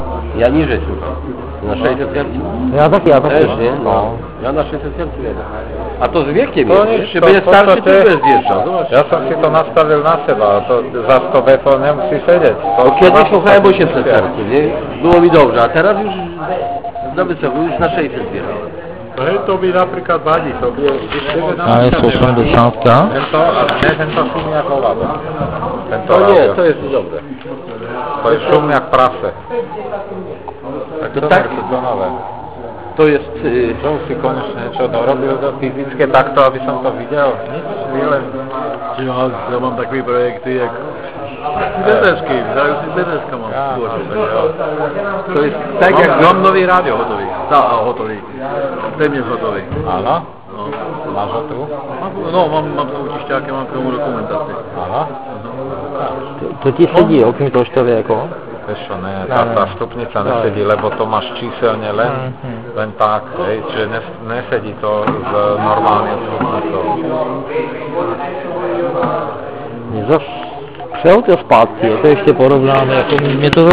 Zde pozor, vše co zde uslyšíte jsou "panelové diskuse" a občas padne i nějaké slovíčko..HI. Např. jsem měl záznam příjmu LIBRY 80, ale tam těch slovíček padlo více a tak to tady nenajdete HI.